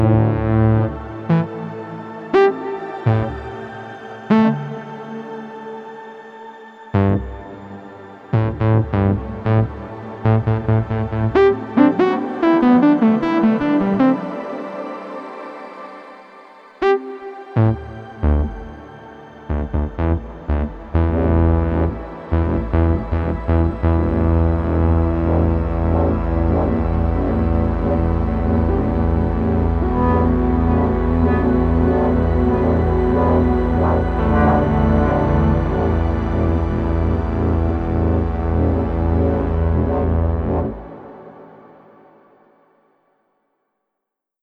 FLAME "FM KOSMOS" Quad polyphonic FM synthesizer
5 - FX Distortion Pad + Reverb
5_FXDistPad_Reverb.wav